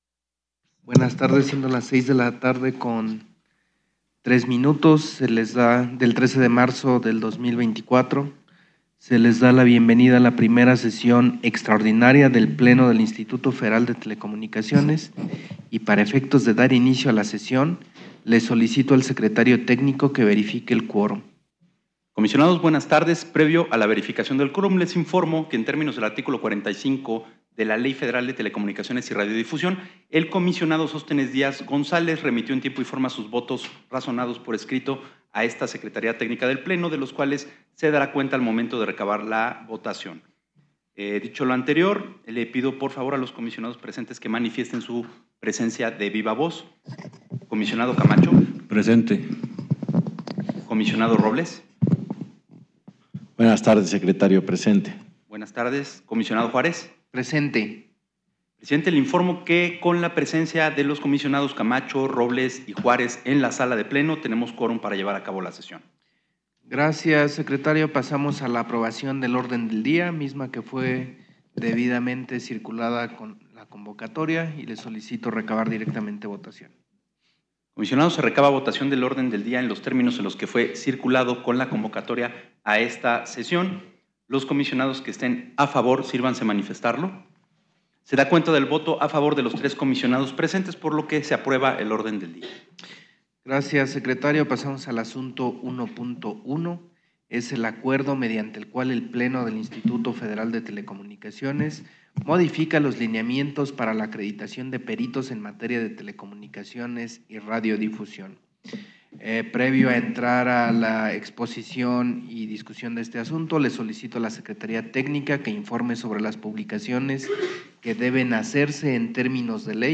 I Extraordinaria del Pleno 13 de marzo de 2024 | Comisión Reguladora de Telecomunicaciones - IFT